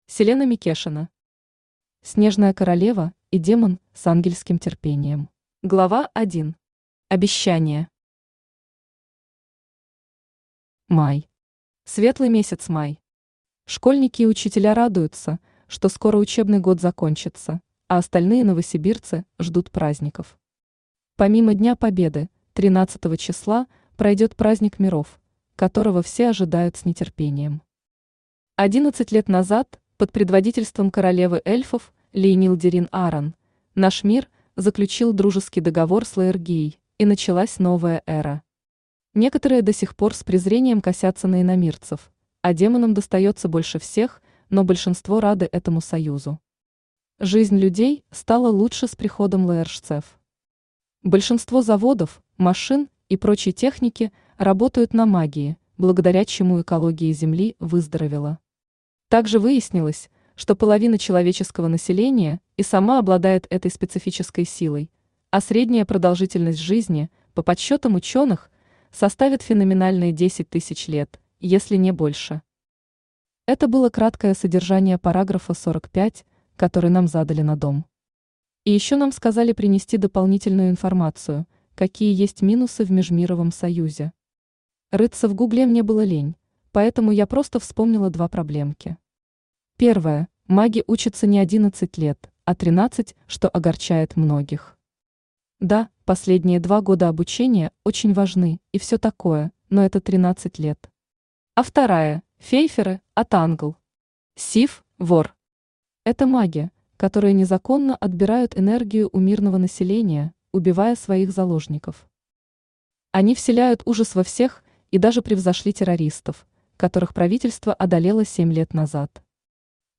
Аудиокнига Снежная королева и демон с ангельским терпением | Библиотека аудиокниг
Aудиокнига Снежная королева и демон с ангельским терпением Автор Селена Микешина Читает аудиокнигу Авточтец ЛитРес.